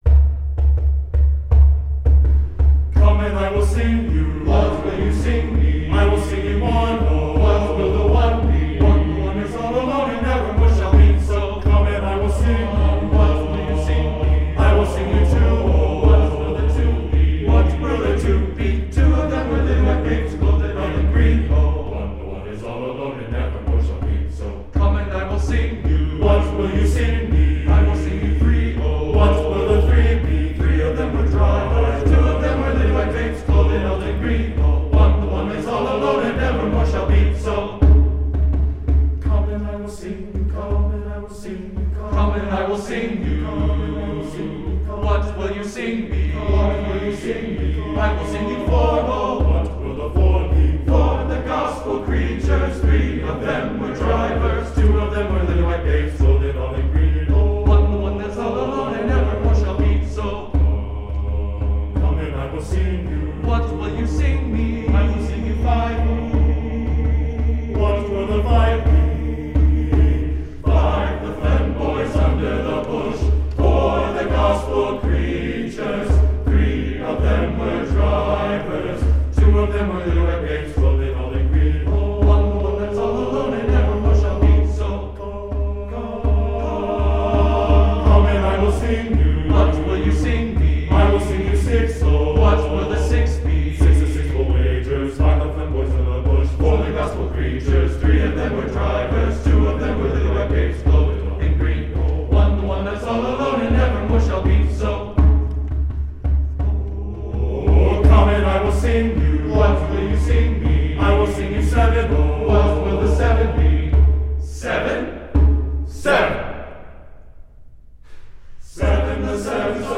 Voicing: TTBB a cappella and Percussion